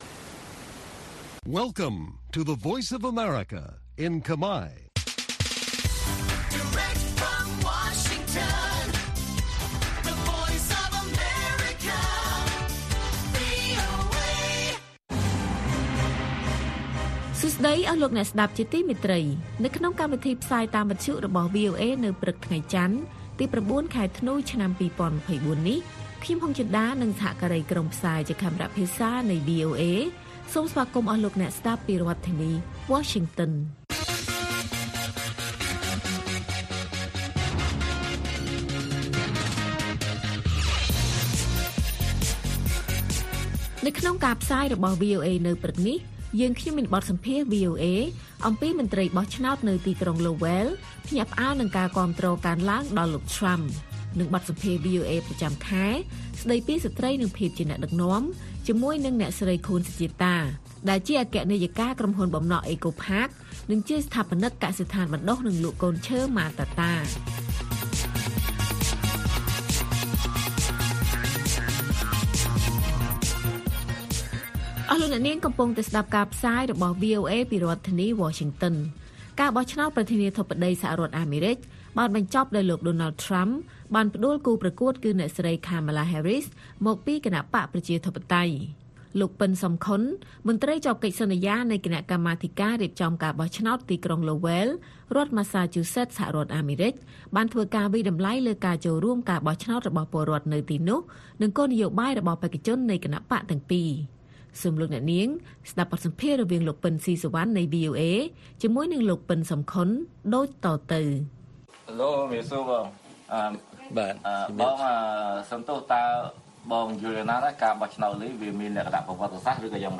ព័ត៌មានពេលព្រឹក ៩ ធ្នូ៖ បទសម្ភាសន៍ VOA អំពីមន្ត្រីបោះឆ្នោតនៅក្រុងឡូវែលភ្ញាក់ផ្អើលនឹងការគាំទ្រកើនឡើងដល់លោក Trump